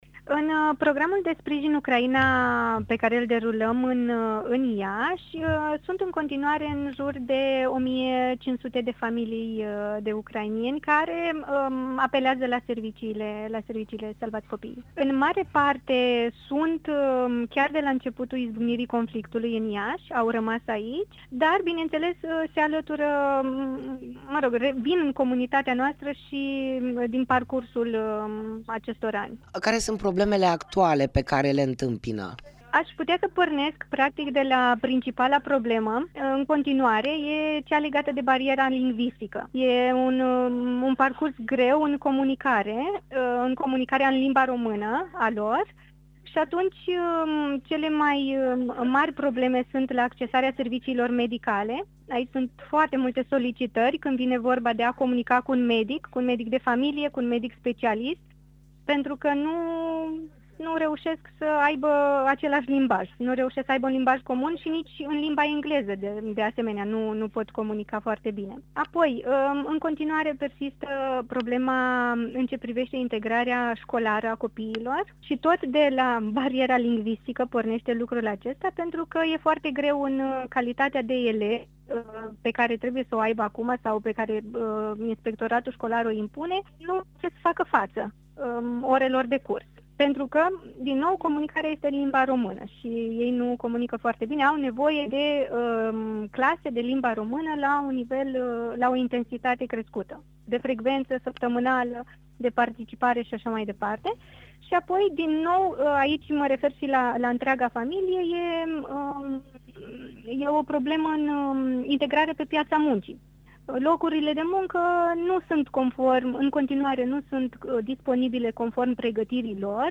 Interviu-Salvati-Copiii-Ucraina-Iasi.mp3